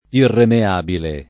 [ irreme # bile ]